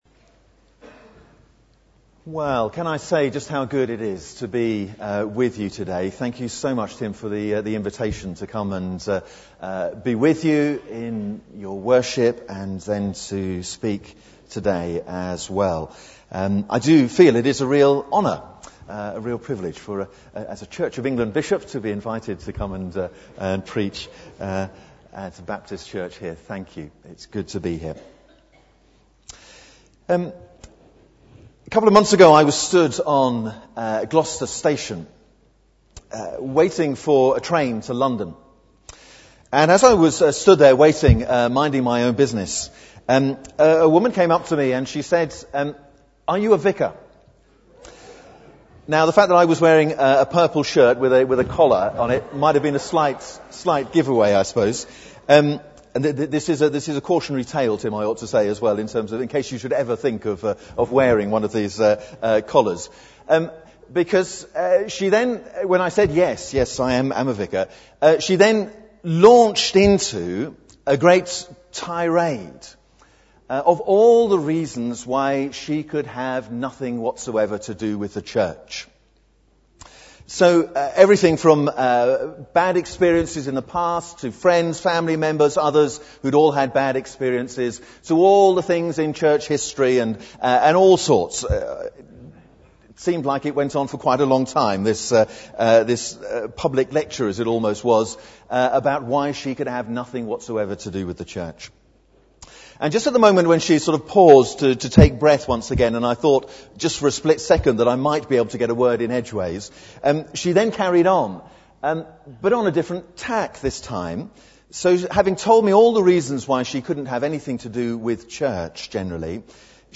Bible Text: Acts 1:1-11 | Preacher: Rt Rev Martyn Snow (Bishop of Tewkesbury) | Series: Guest Speaker
Rt Rev Martyn Snow (Bishop of Tewkesbury) speaking as part of our Guest Speaker series